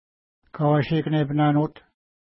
Pronunciation: ka:wa:ʃəkənepəna:nu:t
Pronunciation